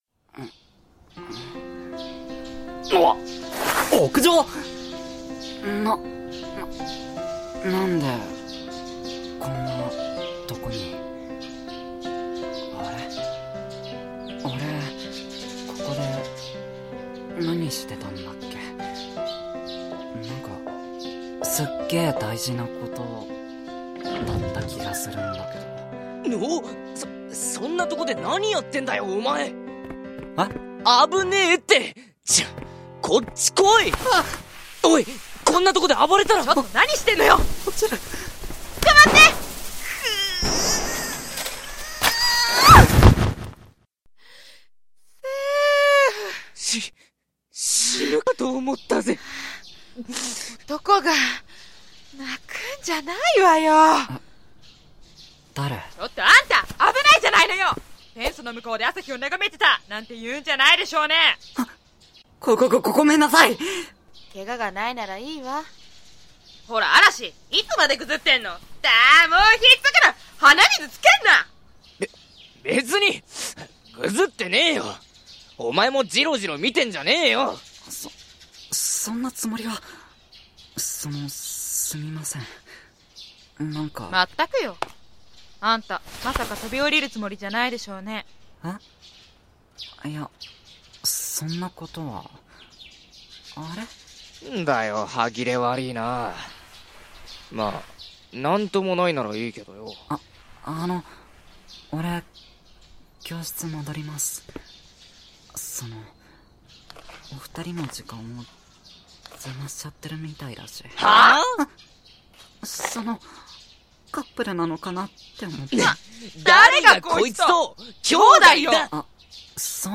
当サイトはボイスドラマが主となります。
Drama